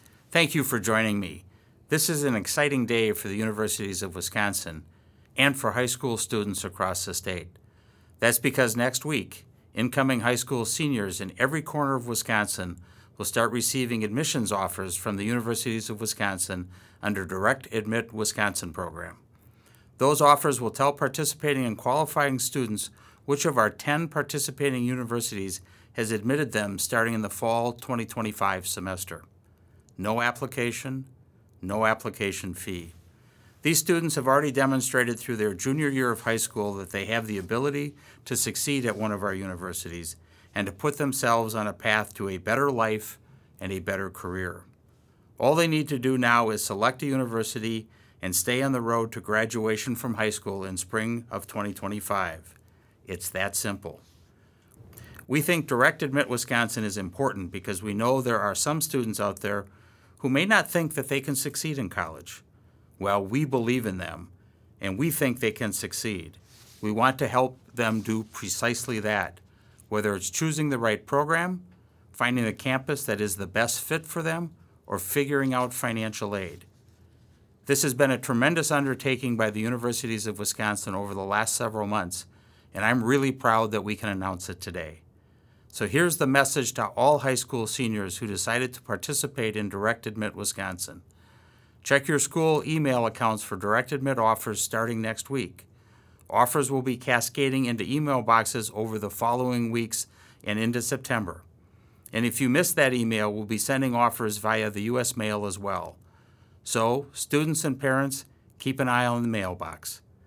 President Rothman audio message about Direct Admit Wisconsin